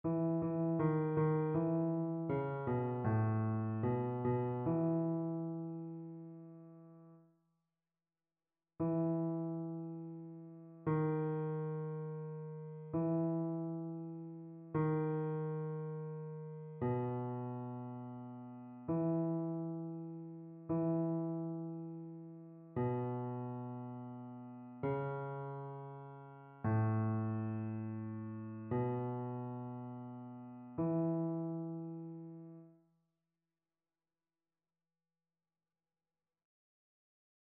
Basse
annee-b-temps-ordinaire-19e-dimanche-psaume-33-basse.mp3